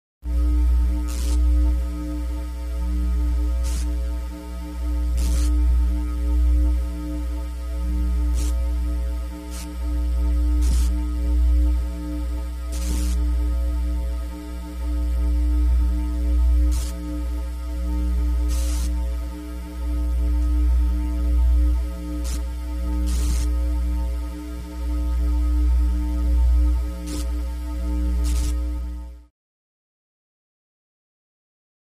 Lab / Spaceship Ambience; Eerie Ambience With Intermittent Electronic Crackles.